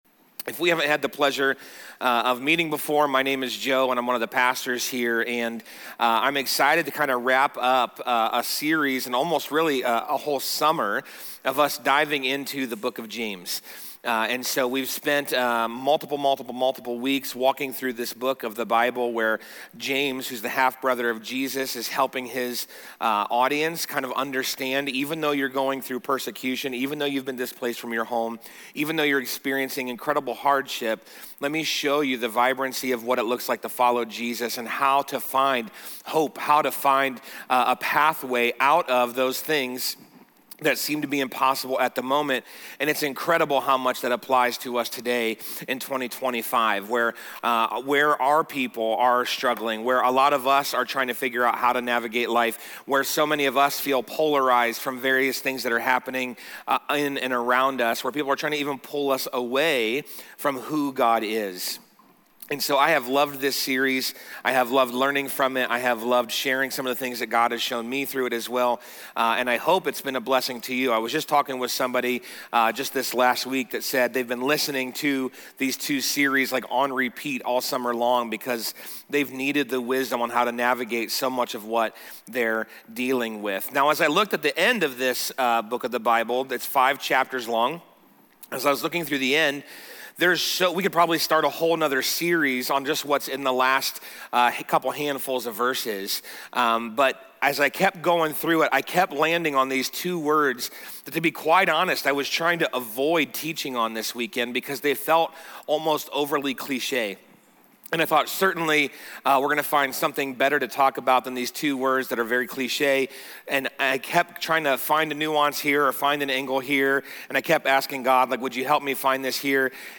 The Bath Campus of Grace Church features services on Saturday nights and Sunday mornings.